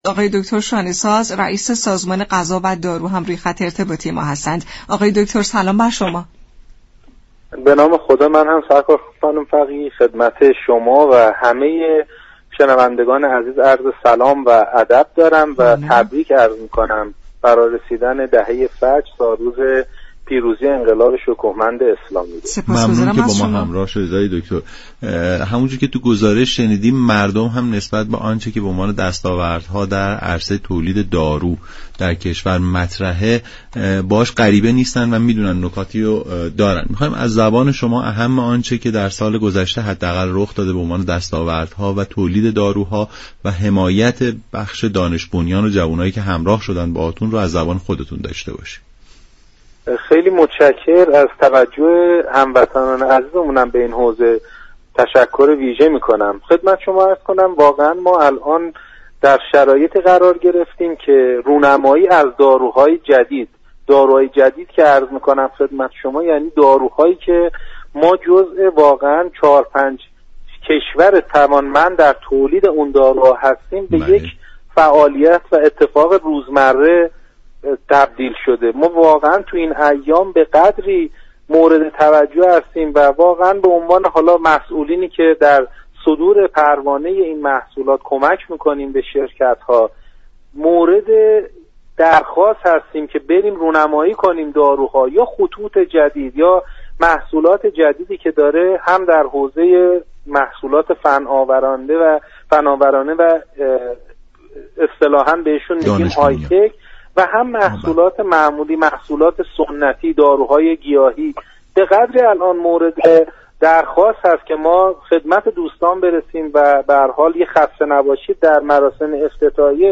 رییس سازمان غذا و دارو در گفت و گو با رادیو ایران گفت: تا پایان سال قرار است دو كارخانه یكی كاملا ایرانی و دیگری تحت پوشش یك نشان معروف خارجی كار تولید انسولین را در كشور انجام دهند.